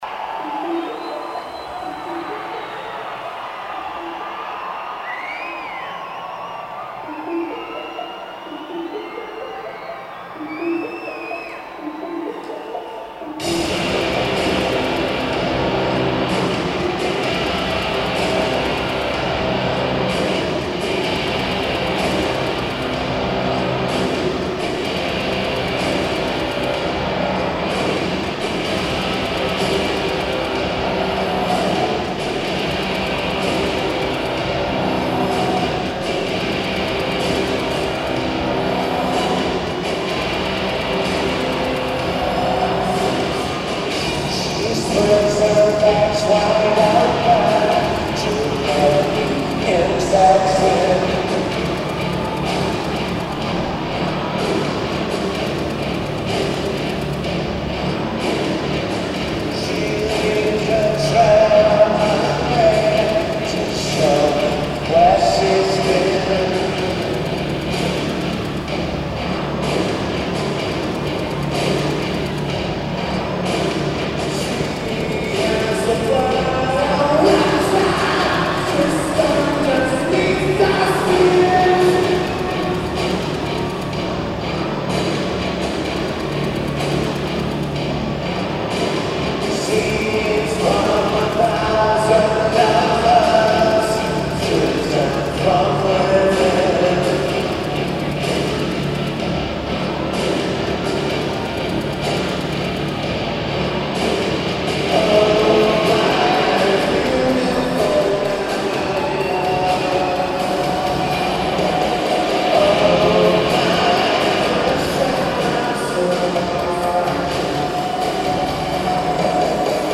The Palace of Auburn Hills